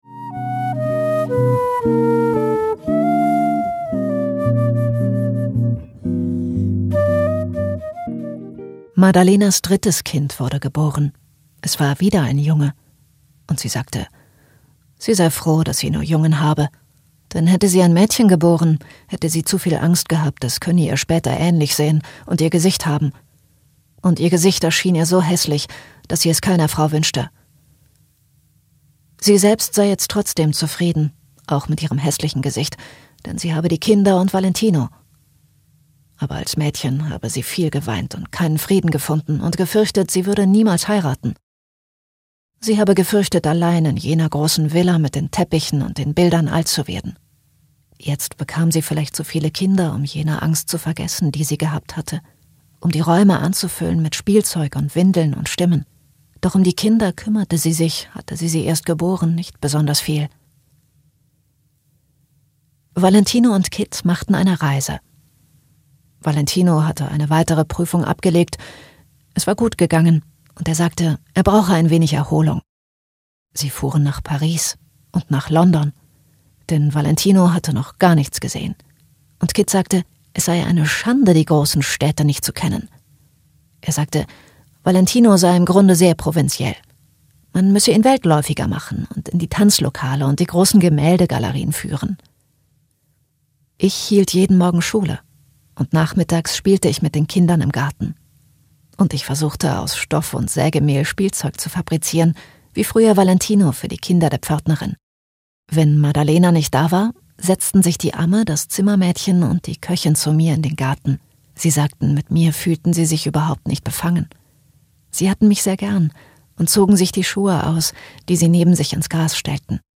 2018 | 1. Hörbuch ungekürzt 4 CDs